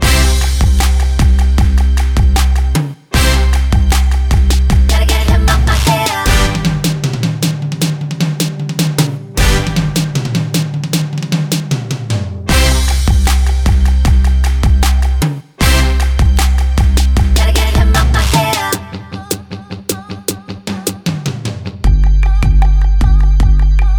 No Ohs Pop (2010s) 3:28 Buy £1.50